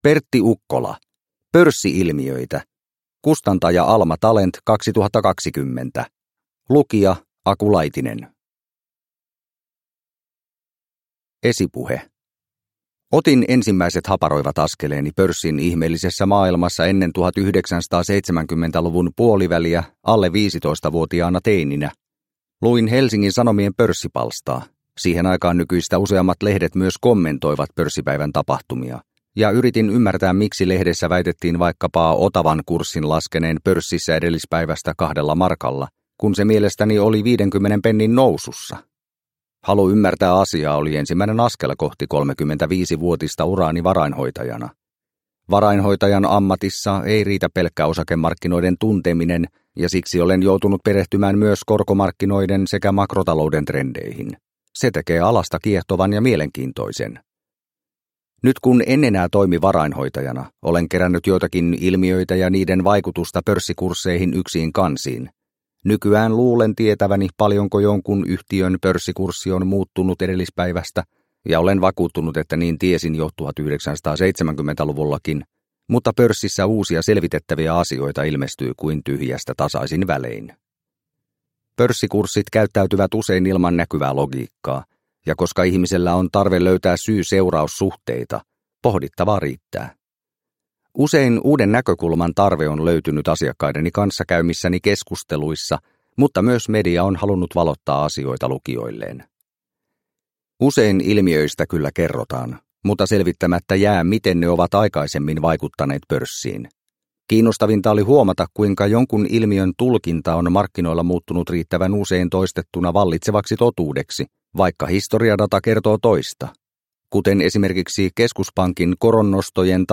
Pörssi-ilmiöitä – Ljudbok – Laddas ner